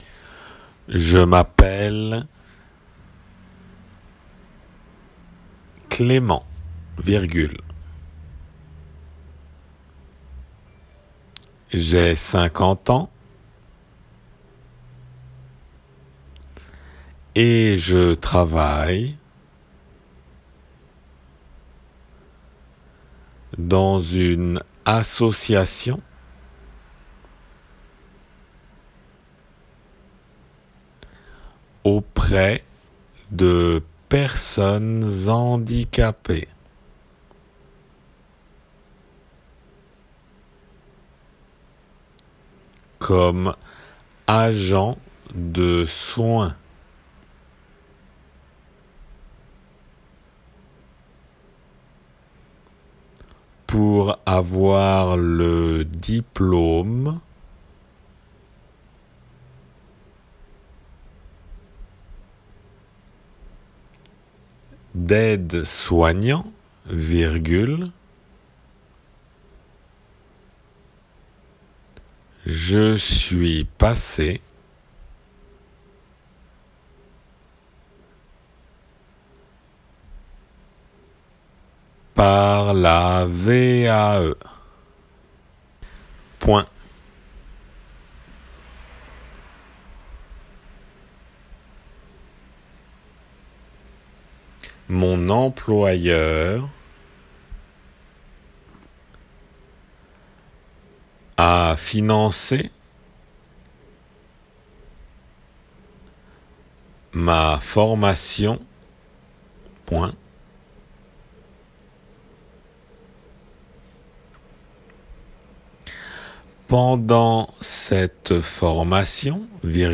デイクテの速さで
2行目　agent de soins. の　(　.   )  point [pwɛ̃] の音が消えてしまっています。